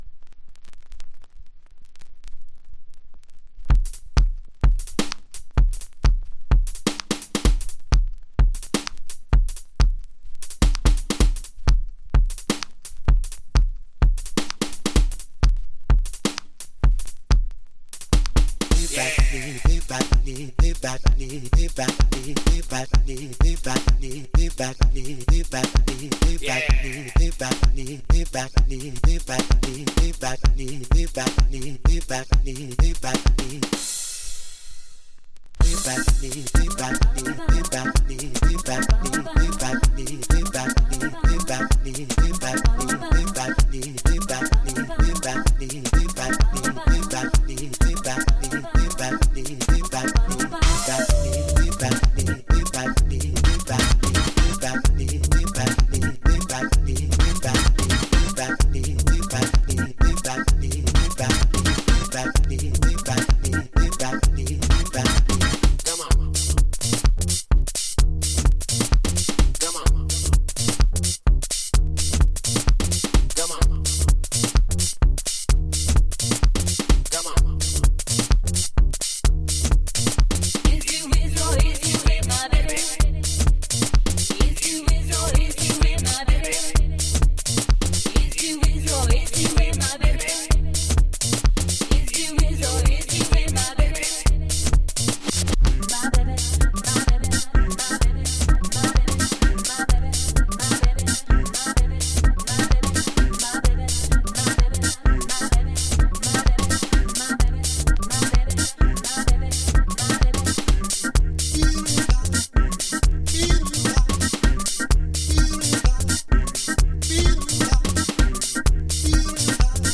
この揺らぎって機材のものなのかreel-to-reel由来のものなのかわかりませんが、結果として良い味になってます！